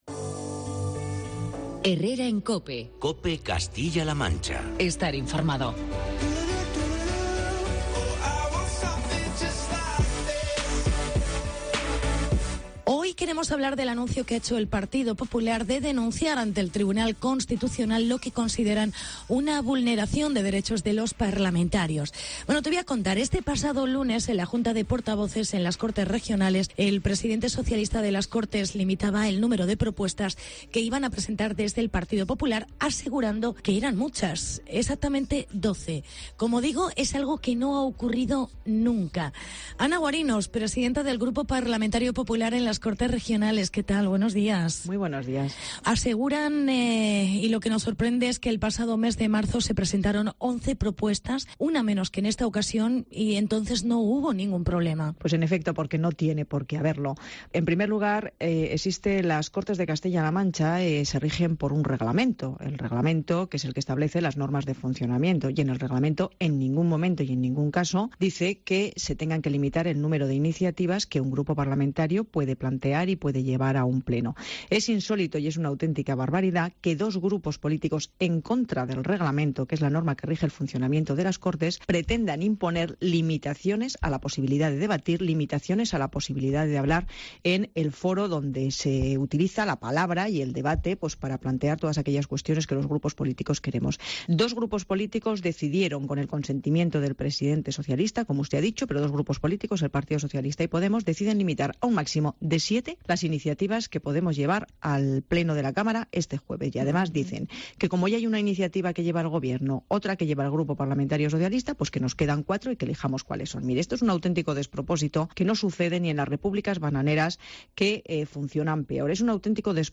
Entrevista con Ana Guarinos. Pta Grupo PP en Cortes CLM